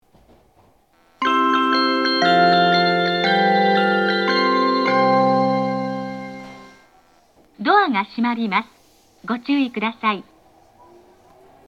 発車メロディー
一度扱えばフルコーラス鳴ります。
スピーカーは番線ごとに分離されています。